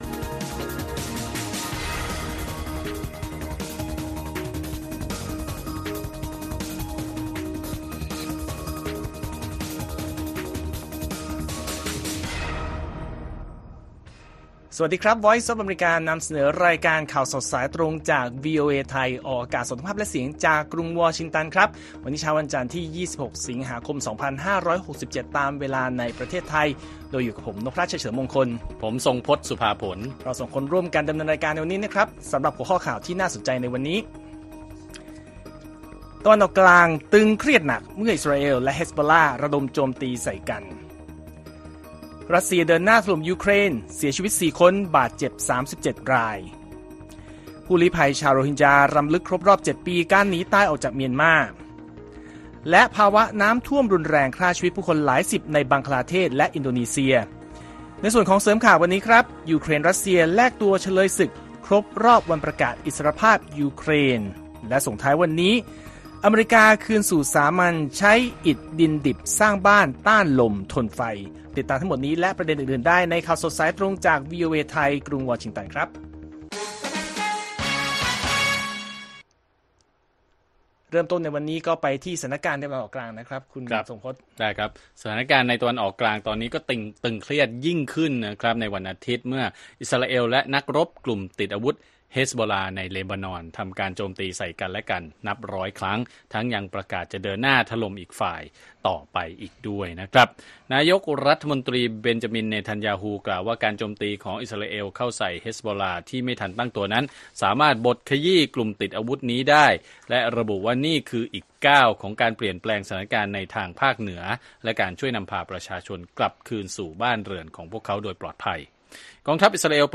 ข่าวสดสายตรงจากวีโอเอไทย จันทร์ ที่ 26 ส.ค. 67